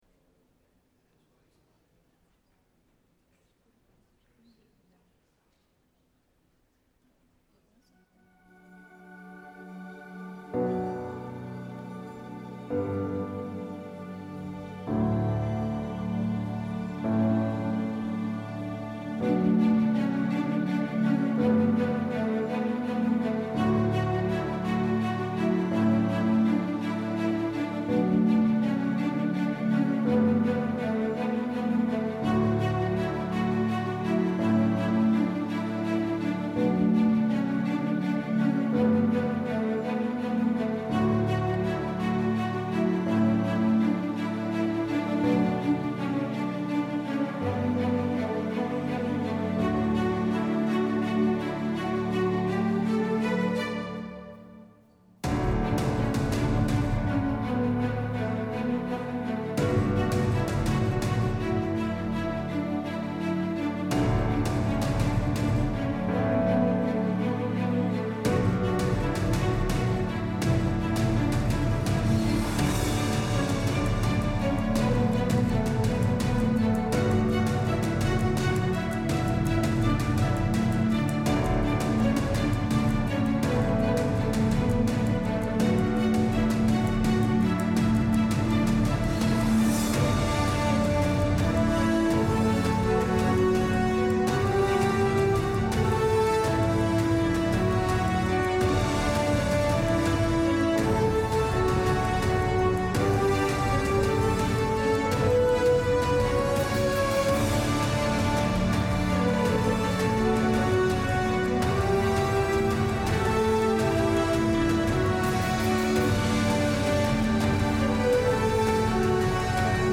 Sunday Sermon November 12, 2023